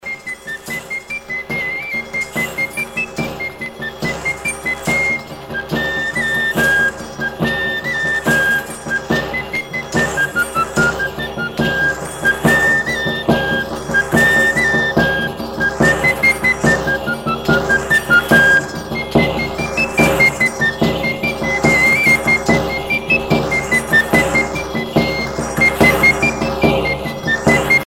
Chivau frus Détails de l'archive Titre Chivau frus Origine du titre : Editeur Note air pour le danse des chevaux-jupons.
danse : rigaudon
Pièce musicale éditée